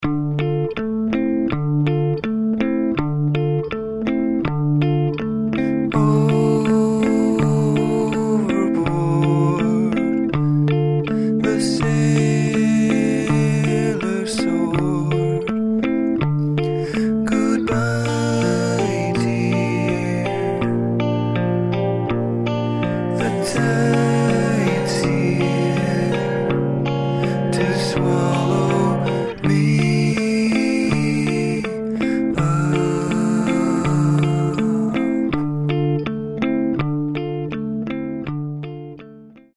cleverly crafted pop songs